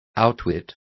Also find out how burlar is pronounced correctly.